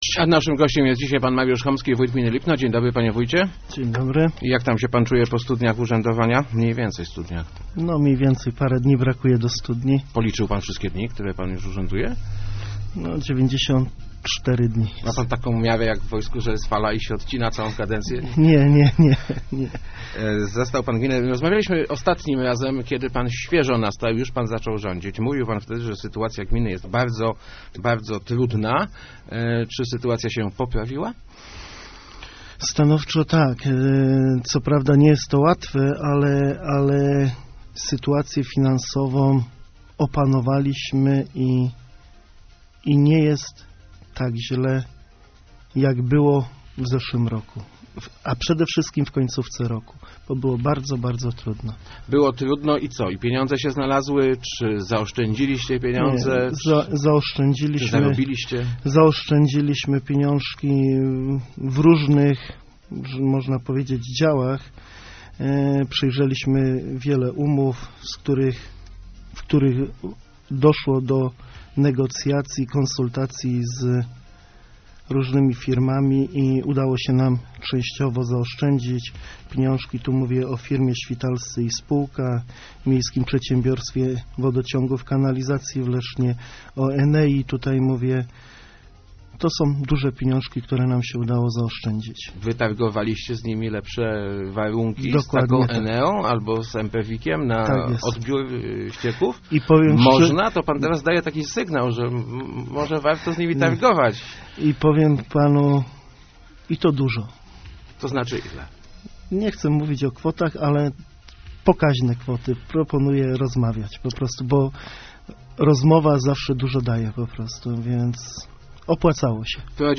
Jestem przeciwny likwidacji szkoły w Górce Duchownej - mówił w Rozmowach Elki wójt Lipna Mariusz Homski. Przyznał on jednak, że należy zracjonalizować wydatki w gminne oświacie.